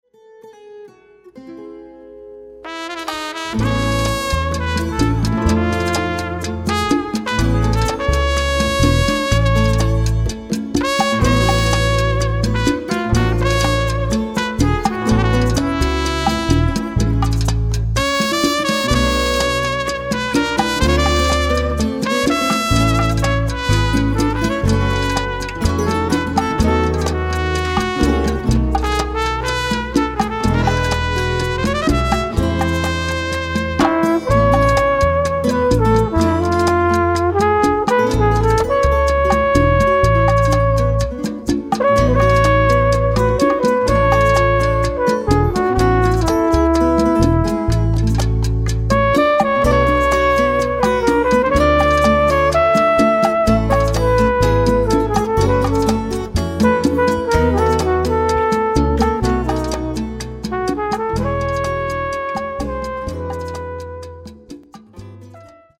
traditioneller Son trifft auf den kreativsten Sänger Cubas.